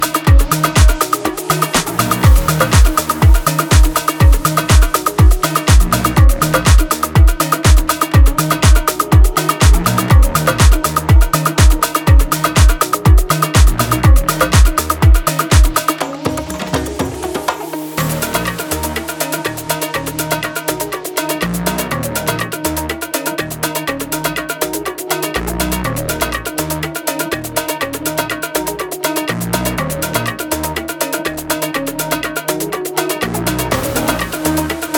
Afro-Pop African Dance
Жанр: Поп музыка / Танцевальные